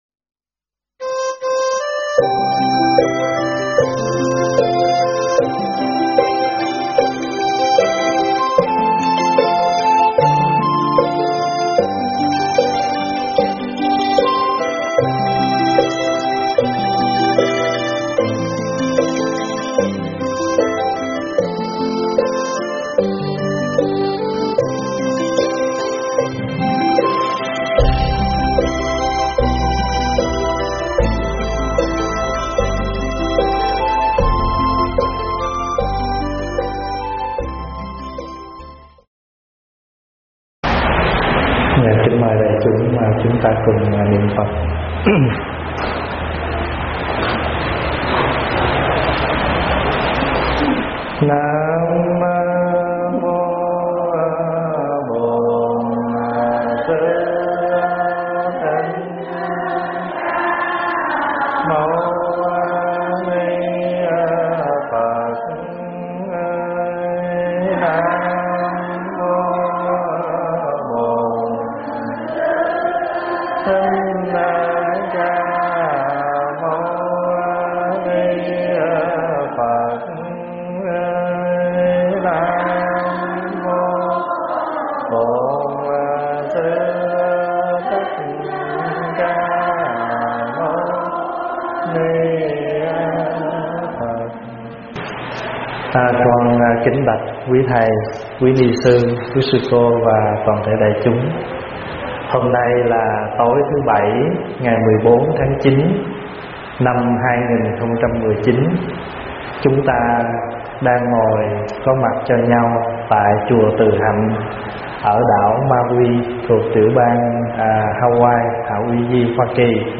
Mp3 thuyết pháp Chánh Mạng
giảng tại Chùa Từ Hạnh Mauii , Hawaii